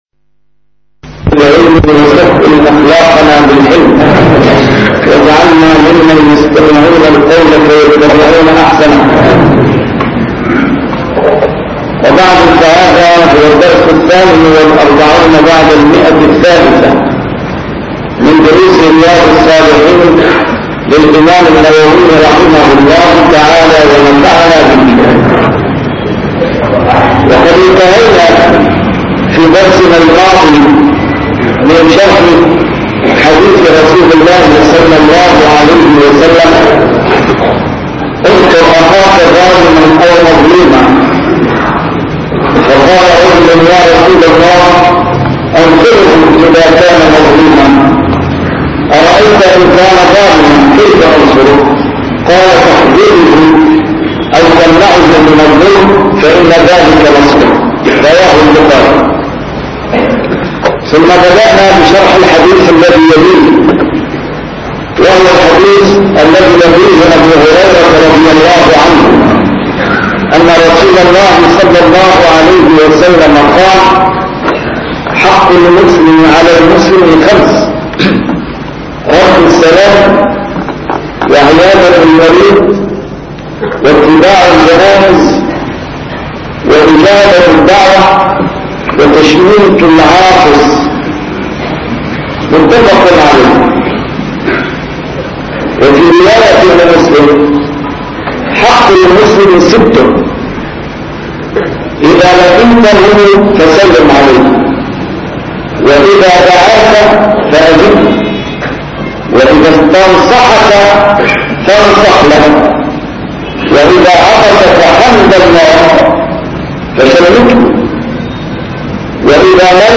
A MARTYR SCHOLAR: IMAM MUHAMMAD SAEED RAMADAN AL-BOUTI - الدروس العلمية - شرح كتاب رياض الصالحين - 348- شرح رياض الصالحين: تعظيم حرمات المسلمين